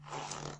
拉链2
描述：在带有Android平板电脑的浴室中录制，并使用Audacity进行编辑。